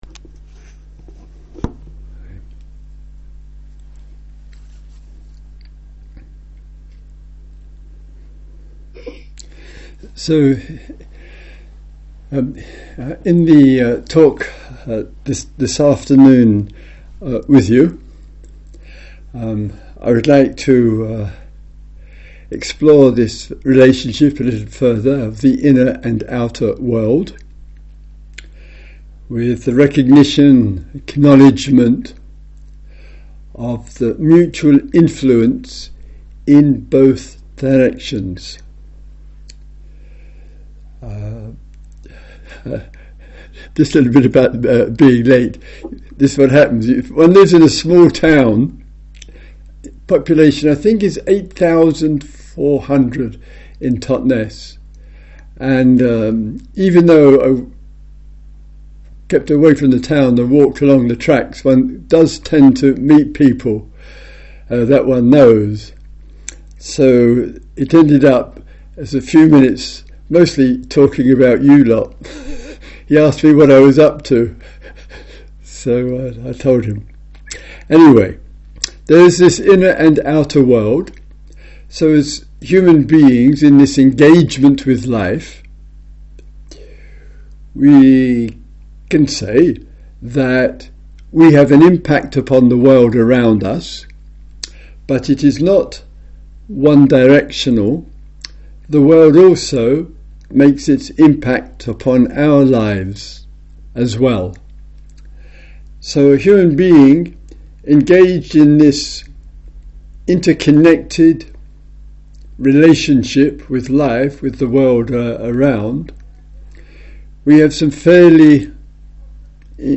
Here are recorded audio talks of Zoom Retreat